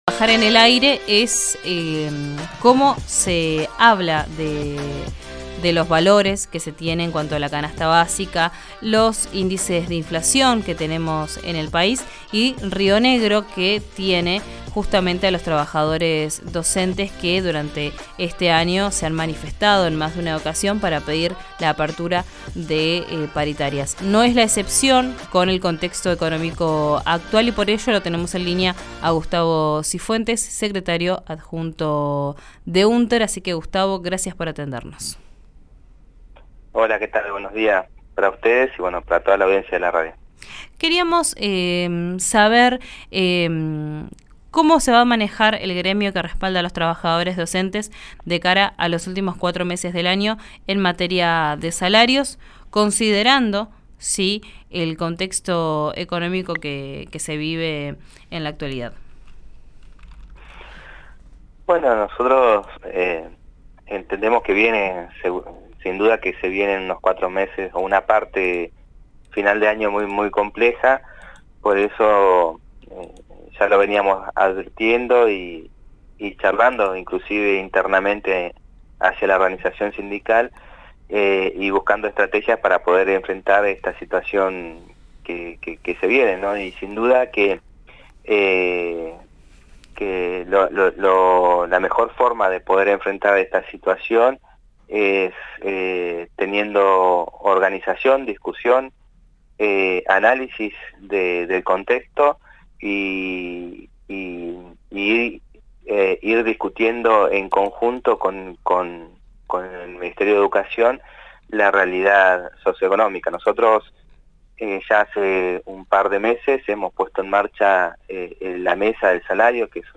En declaraciones durante el programa «Vos al Aire» de RÍO NEGRO RADIO